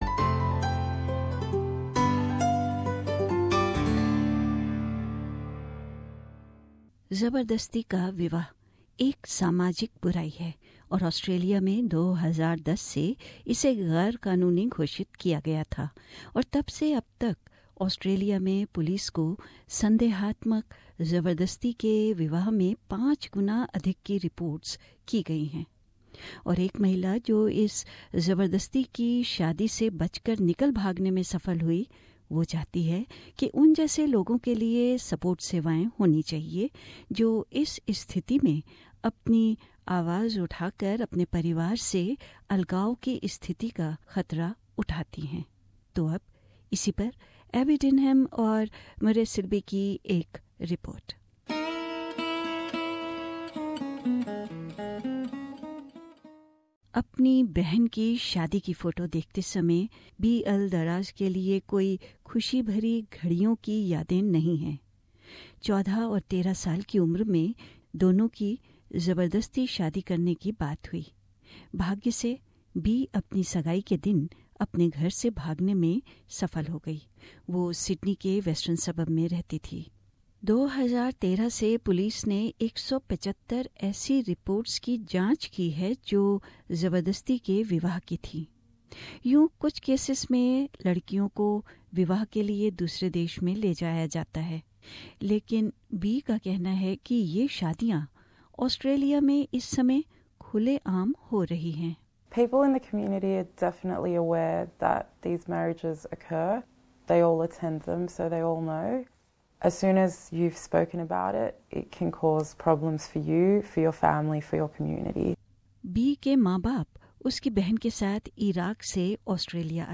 Feature: Forced Marriage